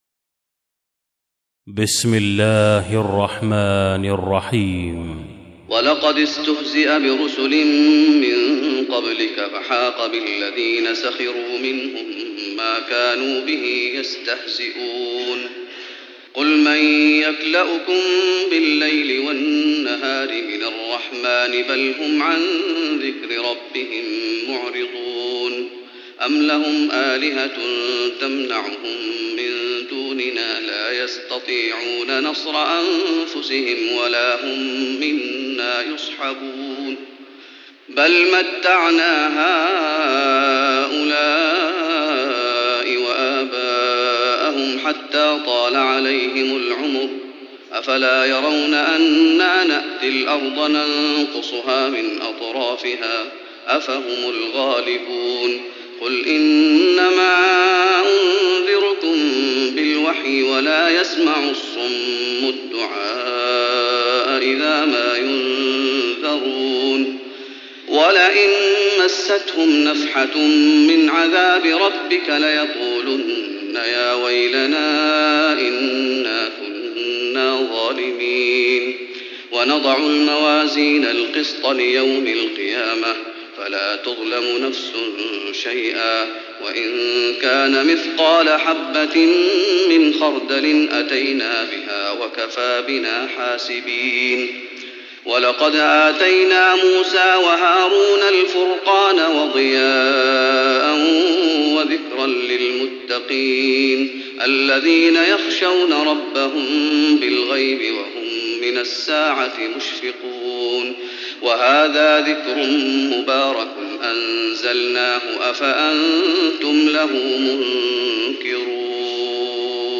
تراويح رمضان 1415هـ من سورة الأنبياء (41-112) Taraweeh Ramadan 1415H from Surah Al-Anbiyaa > تراويح الشيخ محمد أيوب بالنبوي 1415 🕌 > التراويح - تلاوات الحرمين